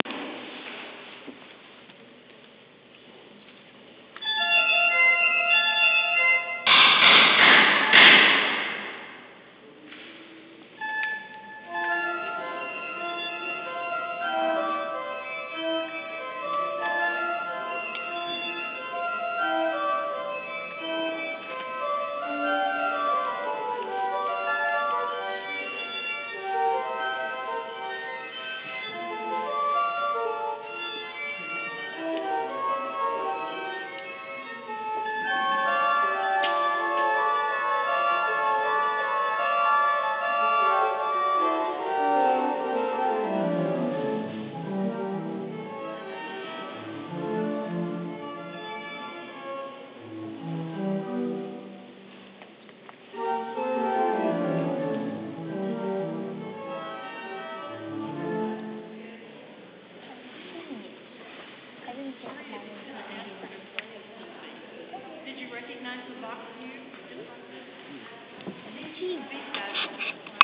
Die Orgel in der Kirche / The Organ in the Church
Eine kleine Demo für uns / A little demo for us